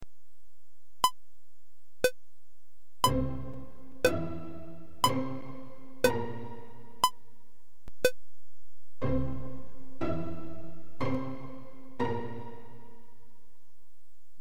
Note, these are all the same tempo; 120 BPM (beats per minute).
2 2 Minim Example Even though I've put four notes in here, you only need to count 2 beats, so it's: 1, 2, 1, 2 (and so on)